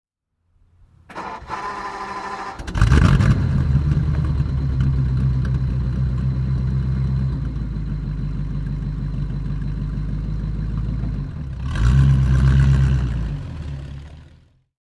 Ford Vedette (1954) - Starten und Leerlauf
Ford_Vedette_1954.mp3